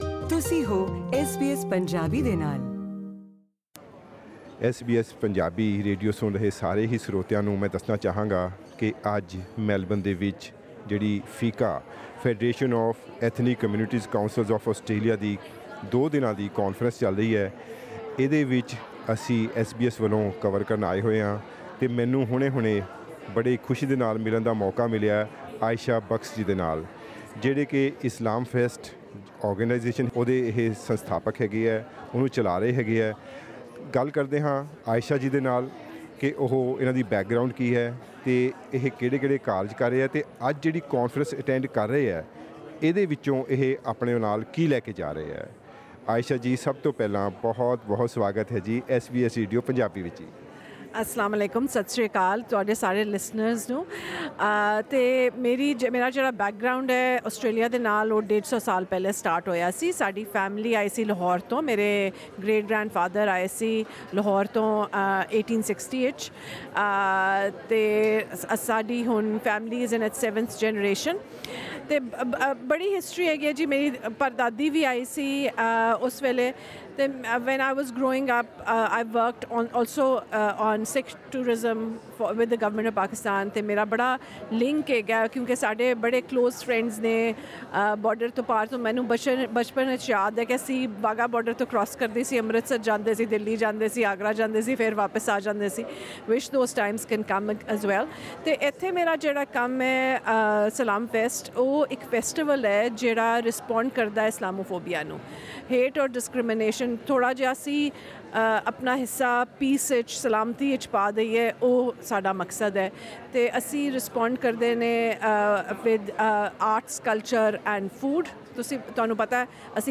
Punjabi-speaking delegates from this year’s Federation of Ethnic Communities Councils of Australia (FECCA) conference, held in Melbourne, spoke about challenges faced by ethnic communities in Australia and how they are contributing towards integration with the mainstream communities.